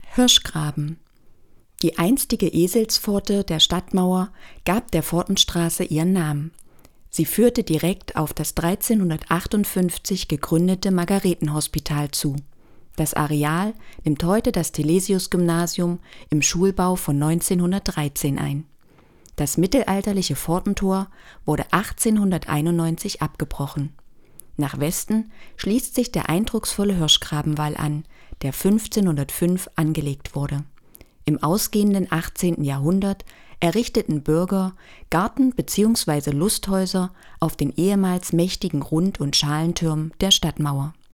Mühlhausen: Beschreibung Mühlhäuser Hirschgraben